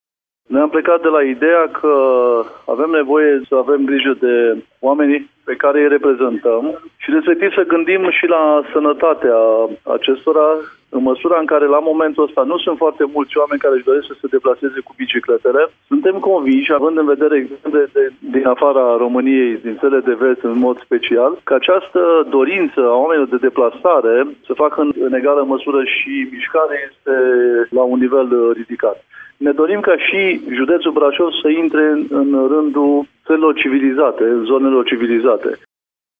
Vicepreședintele Consiliului Județean Brașov, Șerban Toderică: